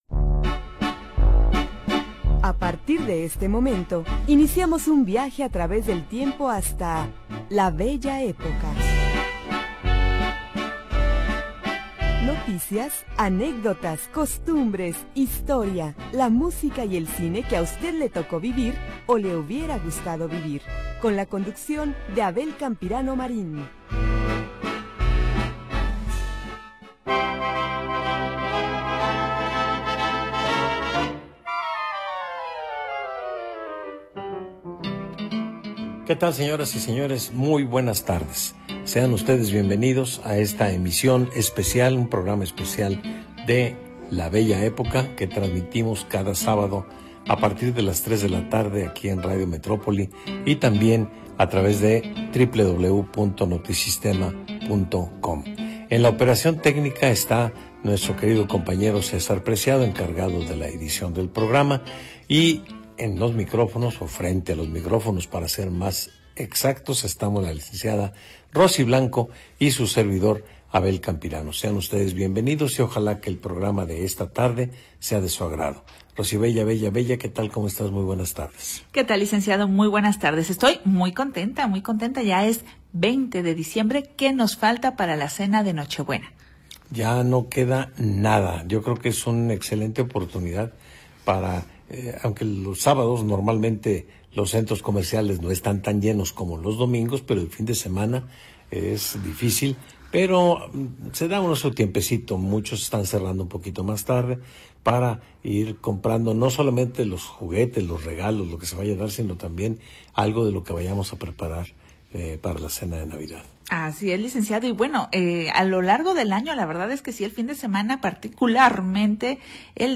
Programa transmitido el 20 de Diciembre de 2025.